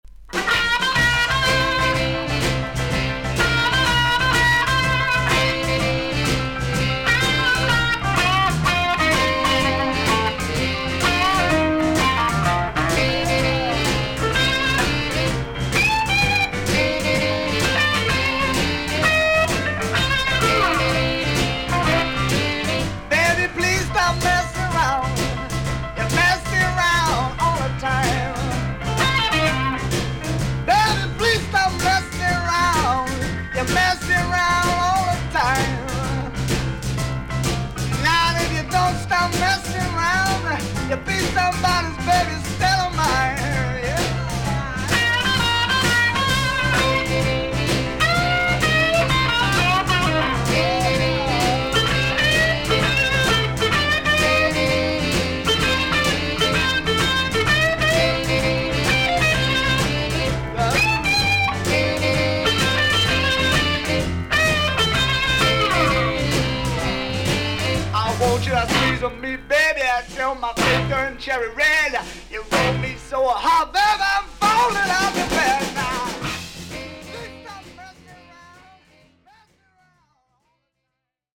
少々軽いパチノイズの箇所あり。少々サーフィス・ノイズあり。クリアな音です。
ブルース・ロック名盤。